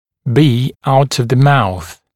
[biː aut əv ðə mauθ][би: аут ов зэ маус]быть не во рту